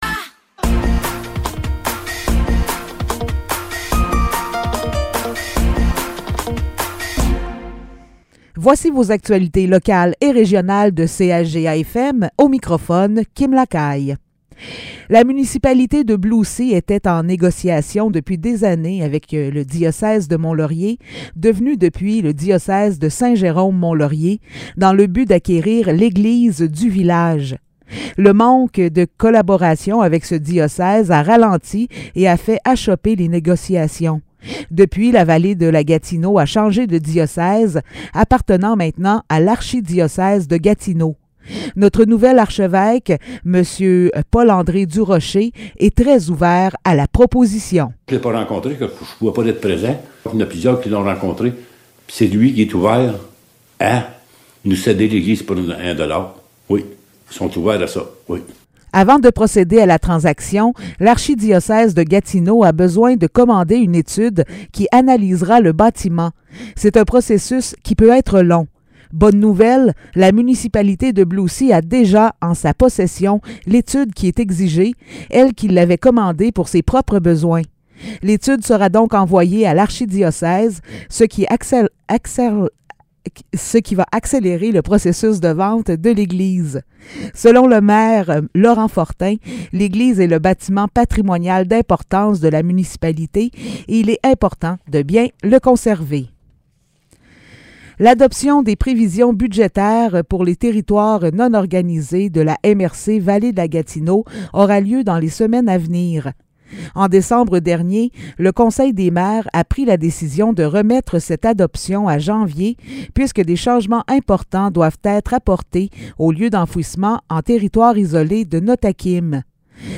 Nouvelles locales - 9 janvier 2023 - 15 h